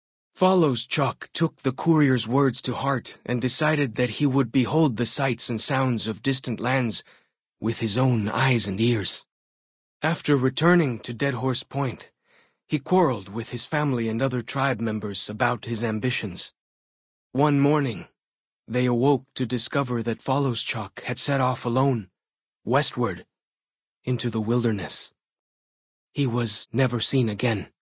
Category:Honest Hearts endgame narrations Du kannst diese Datei nicht überschreiben. Dateiverwendung Die folgende Seite verwendet diese Datei: Enden (Honest Hearts) Metadaten Diese Datei enthält weitere Informationen, die in der Regel von der Digitalkamera oder dem verwendeten Scanner stammen.